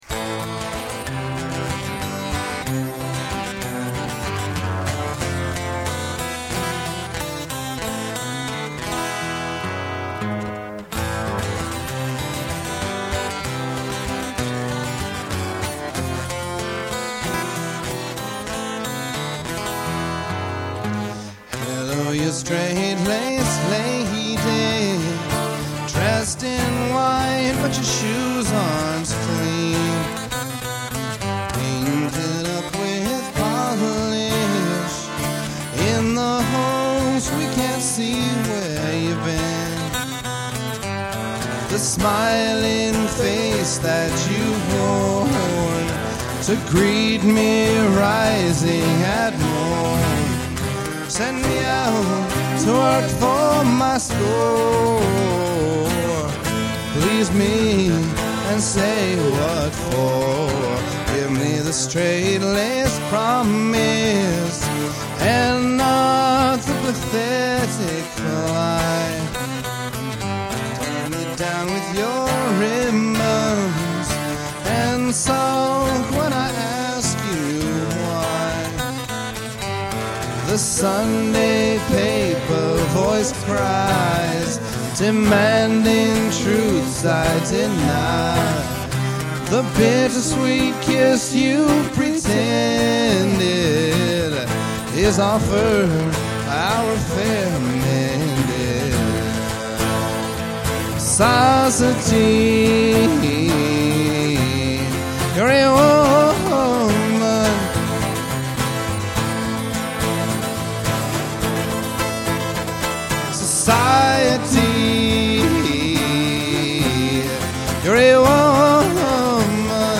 an acoustic guitar duo.